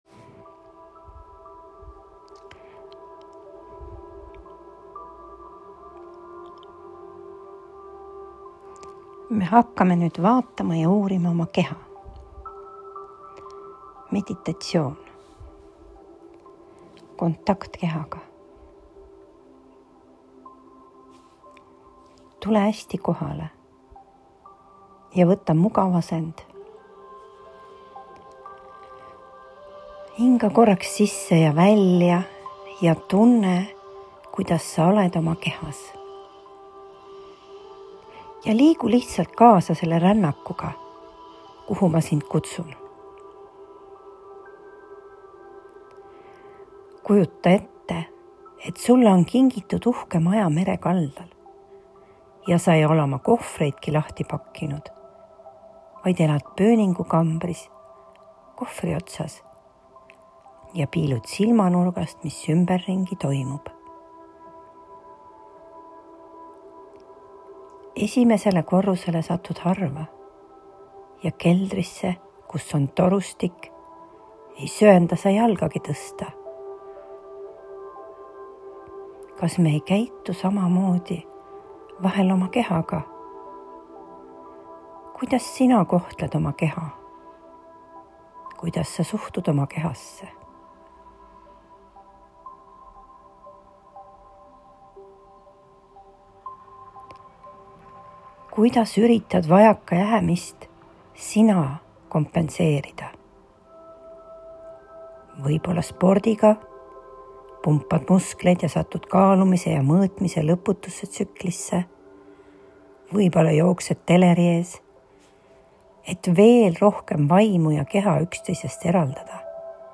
Keha-meditatsioon.m4a